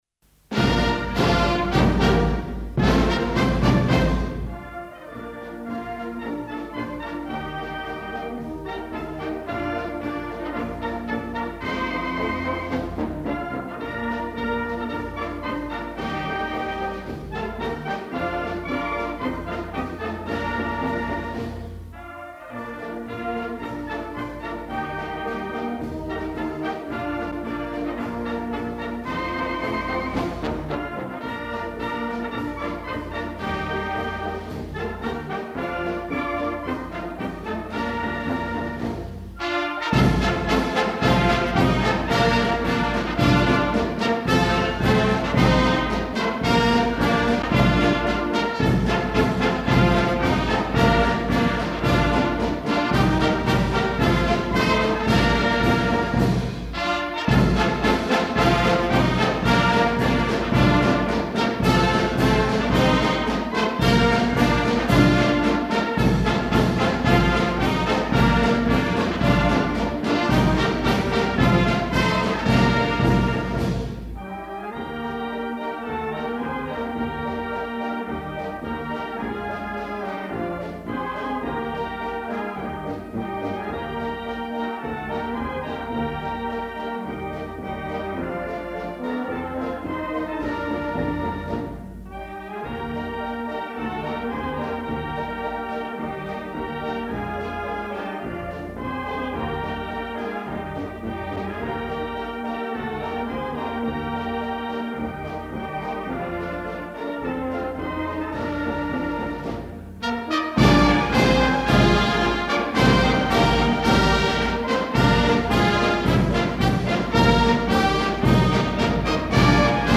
行進曲｢若き日の歓び｣ 「伝承版」　明石高校音楽部　1964（昭和39）年1月23日　明石高校中部講堂